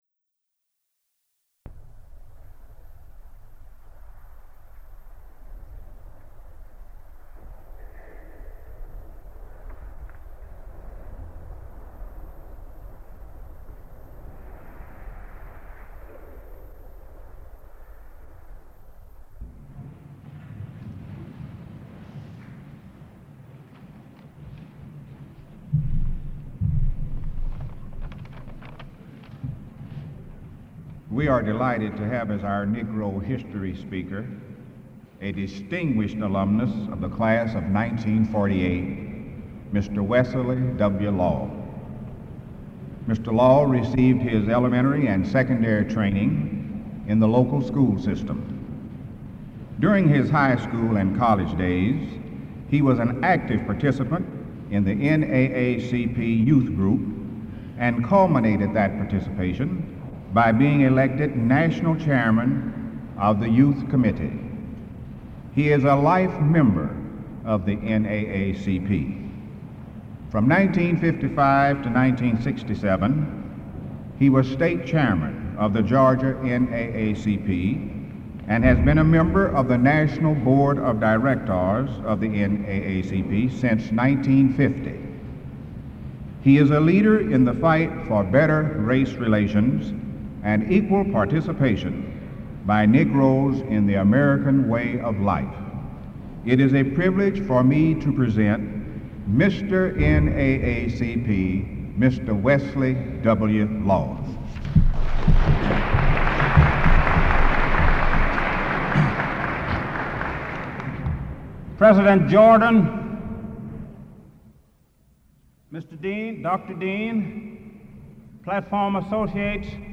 W. W. Law (1923-2002), the main speaker, discussed African American Savannah inventors, African American music, and the importance of books written by and about African Americans.
Object Name Tape, Magnetic Source W. W. Law Foundation Credit line Courtesy of City of Savannah Municipal Archives Copyright Copyright has not been assigned to the City of Savannah.